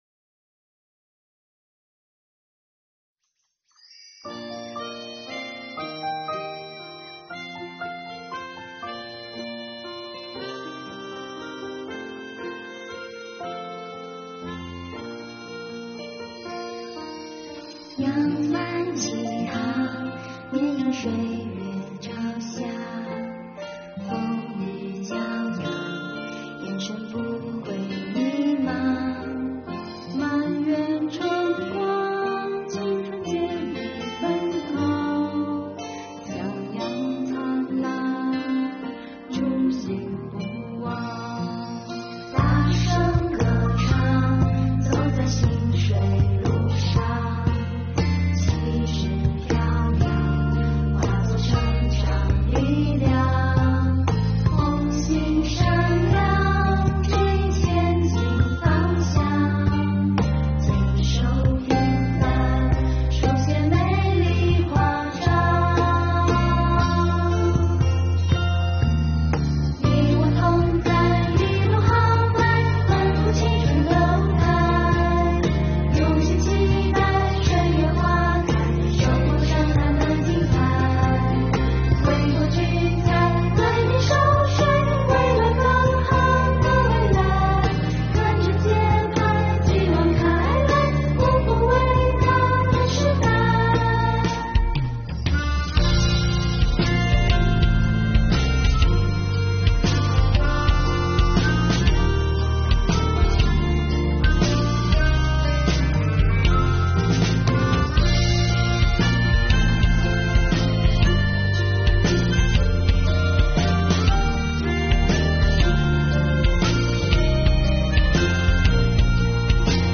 值此中国共产主义青年团百年华诞之际，贵港税务青年自创、自唱、自弹、自演一曲《税月花开》，以青年之名，以青春之歌，以青涩之音，歌唱不负韶华的花开“税”月，唱响“喜迎二十大，永远跟党走，奋进新征程”的税务之声！